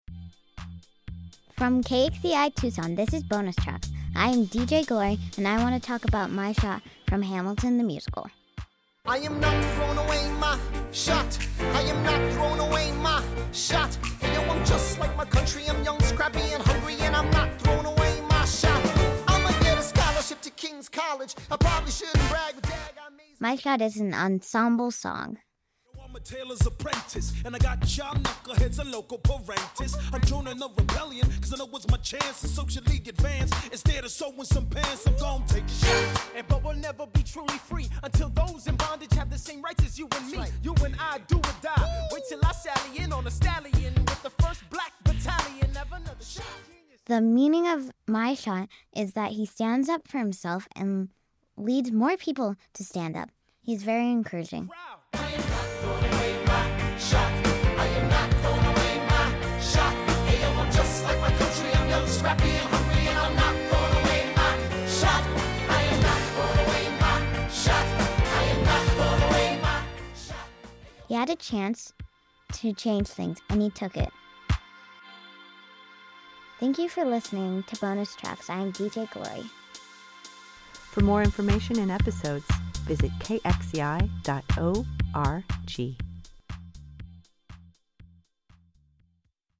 Song Review: “My Shot” from Hamilton